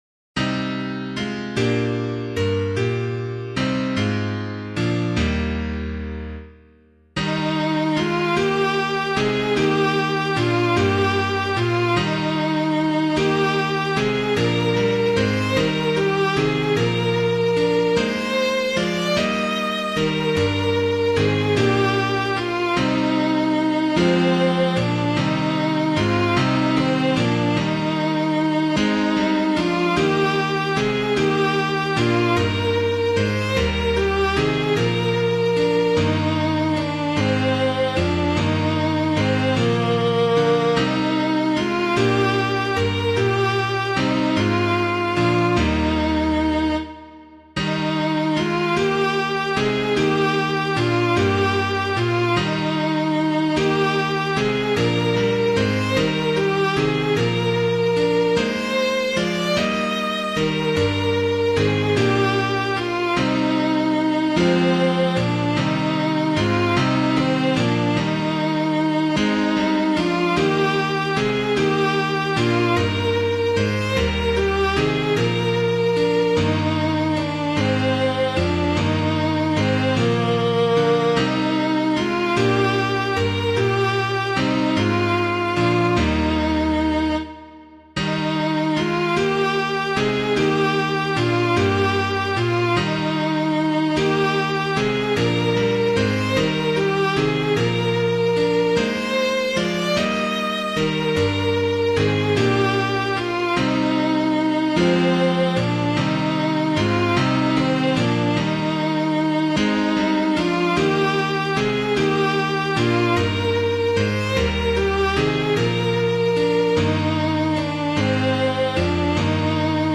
Public domain hymn suitable for Catholic liturgy.
Composer: Sanctus trope, 11th cent.; chant, Mode V; adapted from Piae Cantiones , 1582.
Of the Father's Love Begotten [Neale + Baker - CORDE NATUS] - piano.mp3